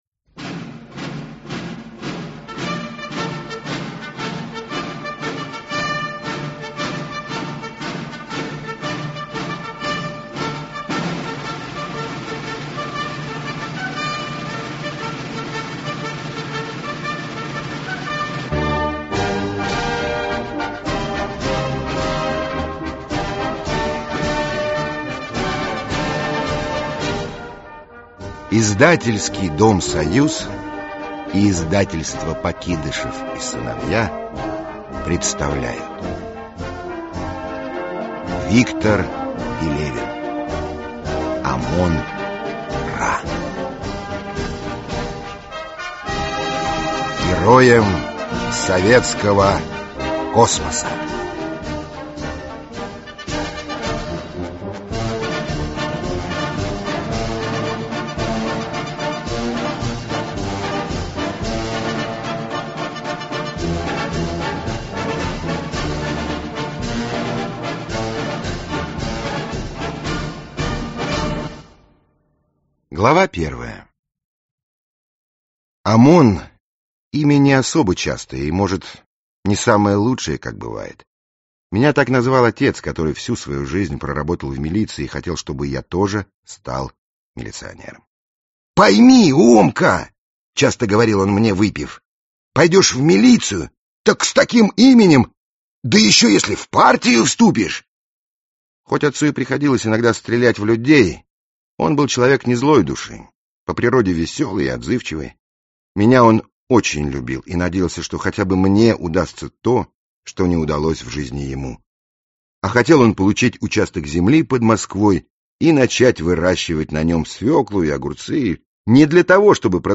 Aудиокнига Омон Ра Автор Виктор Пелевин Читает аудиокнигу Михаил Горевой.